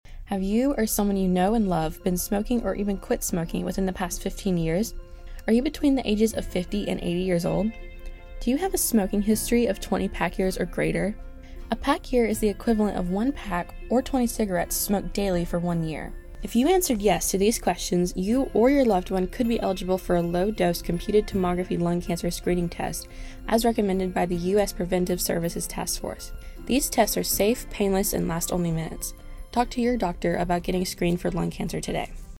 Radio PSAs